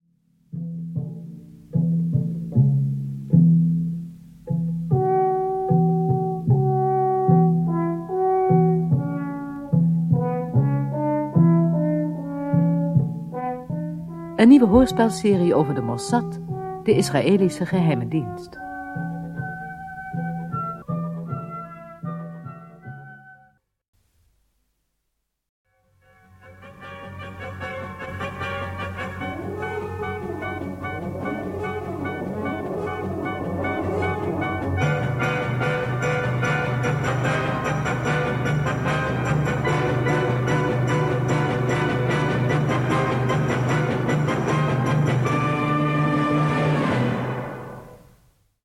De tune van het hoorspel.
U hoort begin- en eindtune van deze hoorspel reeks.